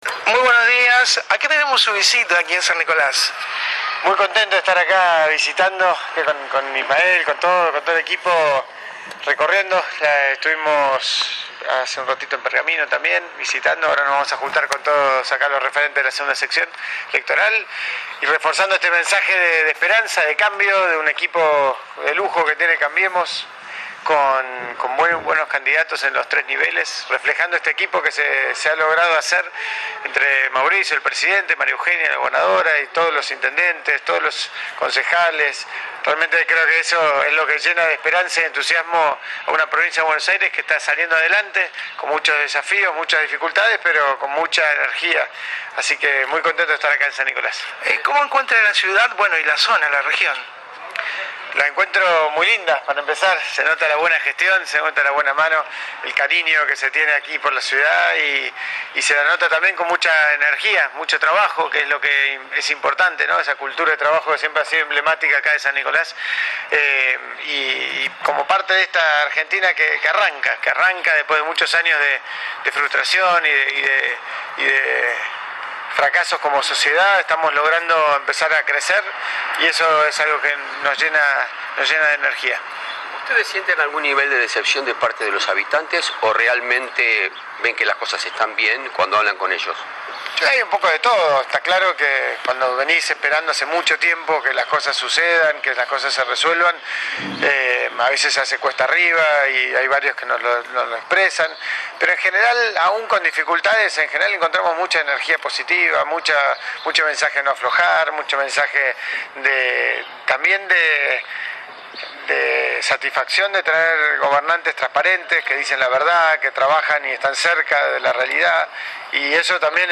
Exactamente a las 13.00 hs. después de haber estado en la ciudad de Pergamino se realizó una rápida y corta conferencia de prensa sobre la vereda peatonal enfrente de la catedral.
Esta es la conferencia.